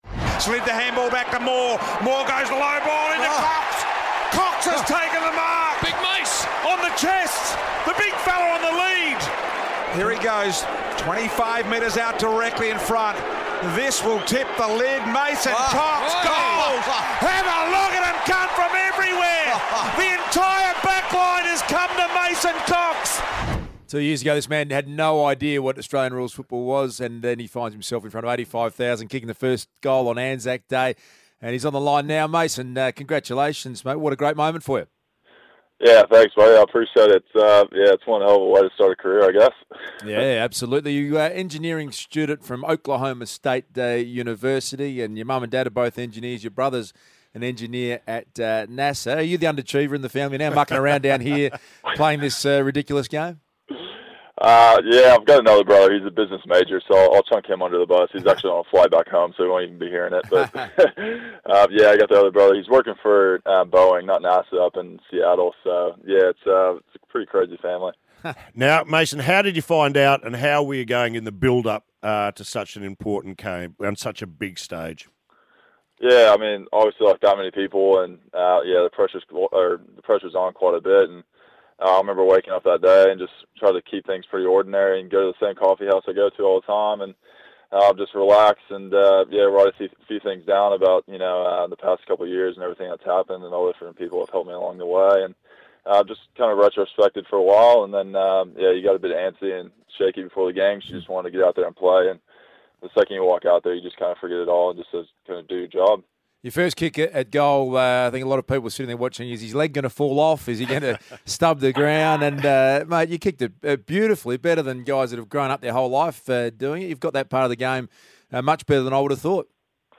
Listen as Mason Cox joins The Hot Breakfast on Triple M the morning after his ANZAC Day debut.